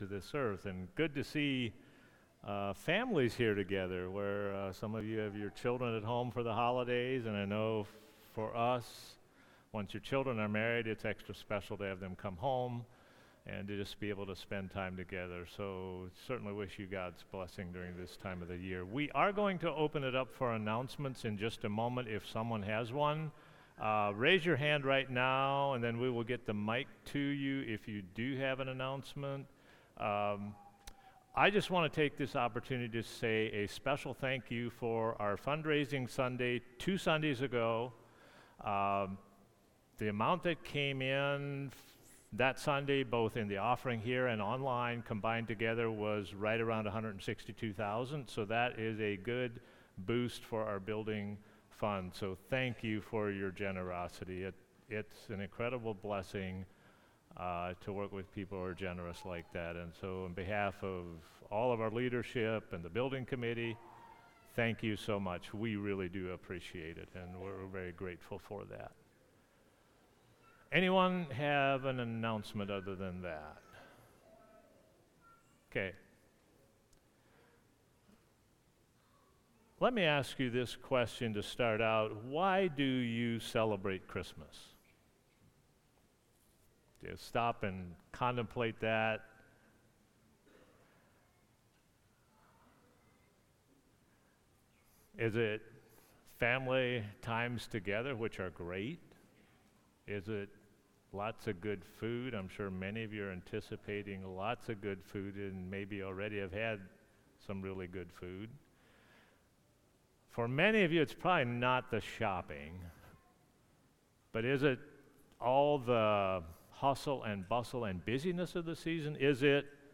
Christmas Service